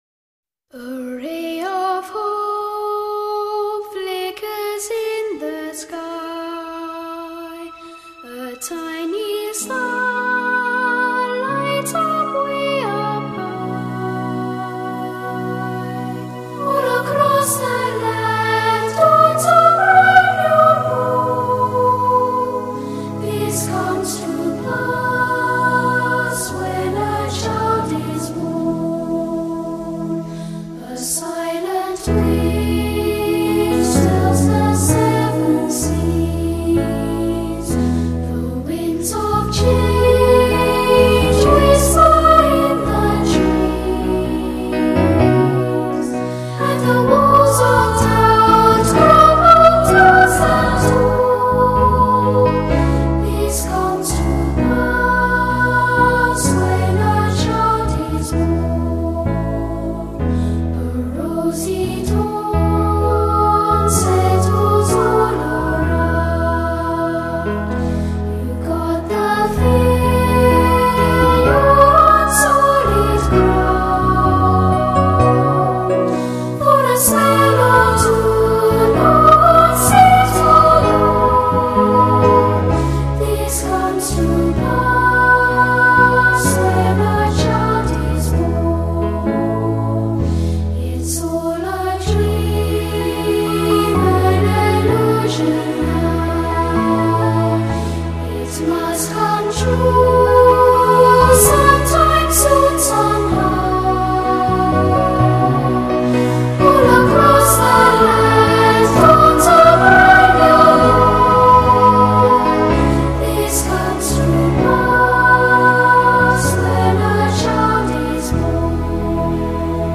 音乐类型：发烧天碟
定位依然精绝，
层次依然丰富与低频变幻依然多姿彩，